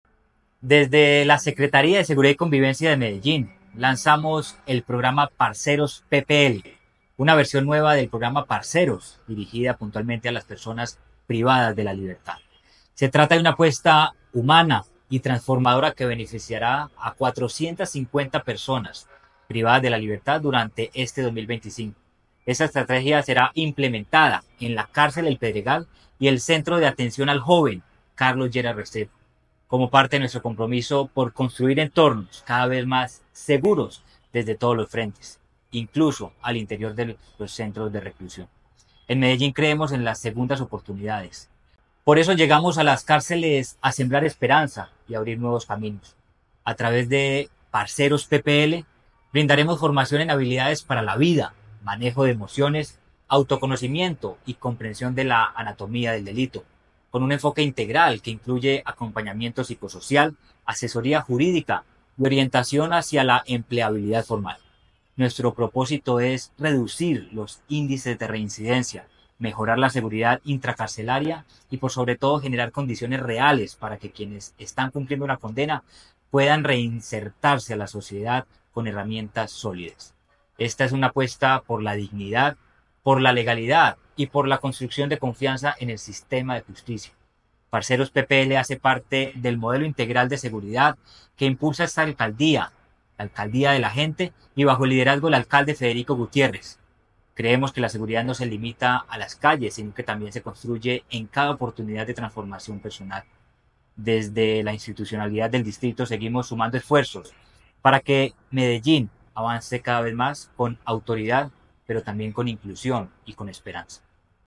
Audio Declaraciones secretario de Seguridad y Convivencia, Manuel Villa Mejía
Audio-Declaraciones-secretario-de-Seguridad-y-Convivencia-Manuel-Villa-Mejia-2.mp3